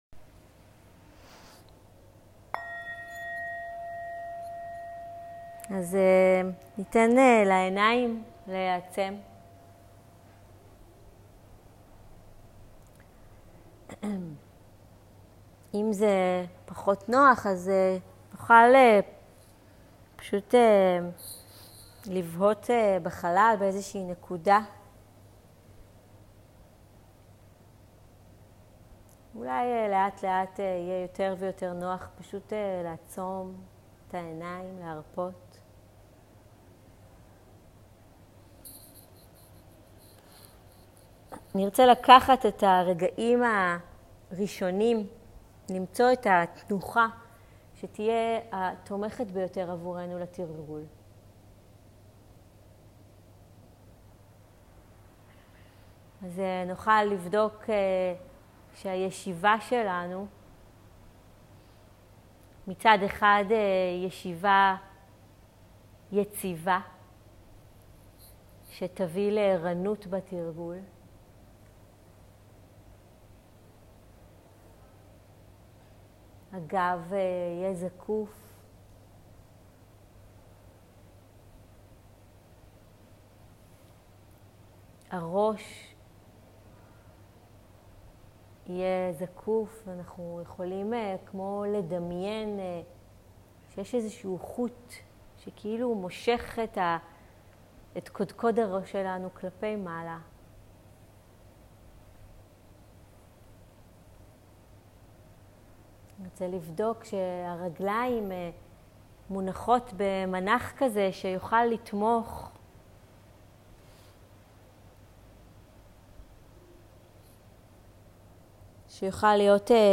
מדיטציה לטיפוח תשומת הלב, שמתחילה בתשומת לב בגוף. הגוף שלנו הוא הבית הפנימי שלנו- בואו נחזור הביתה